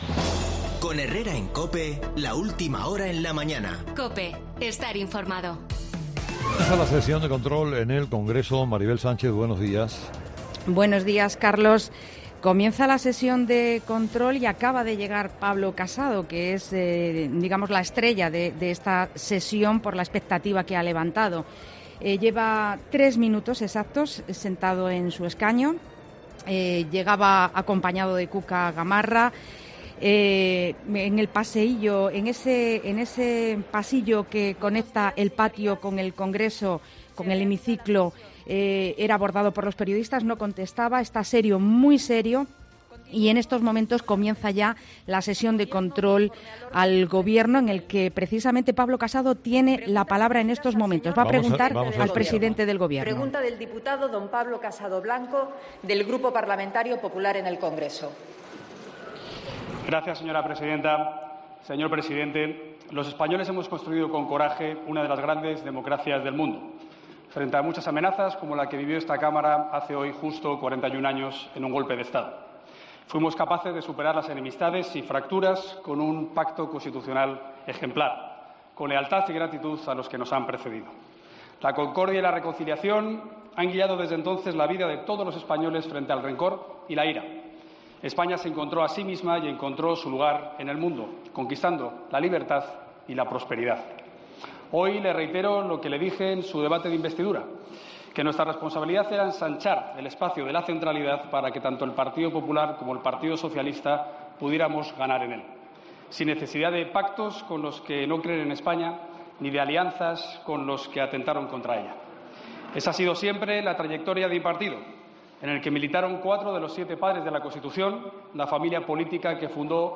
"Entiendo la política desde la defensa de los más nobles principios y valores, desde el respeto a los adversarios y la entrega a los compañeros. Todo para servir a España y a la causa de la libertad", ha proclamado Casado, en un discurso que ha leído y alejado de la tensión de otras sesiones de control y por el que ha recibido un aplauso de su grupo parlamentario.